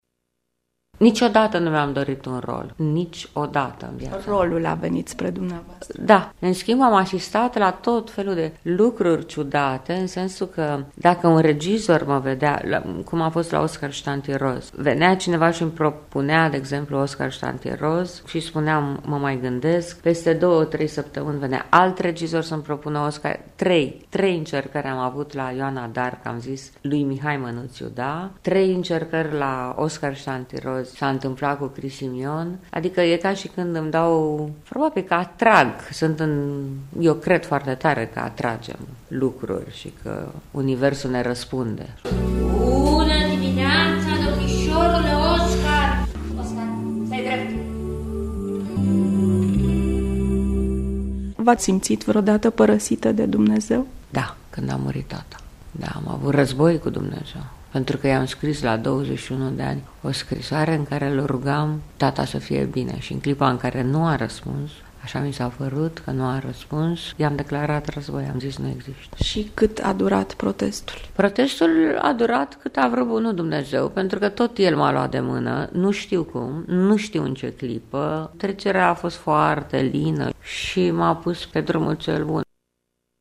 Îi vom asculta povestea de viaţă, înregistrată la Târgu-Mureş, după recitalul cu „Vocea umană” de Jean Cocteau şi vizionarea filmului „Stare de fapt”( în regia lui Stere Gulea).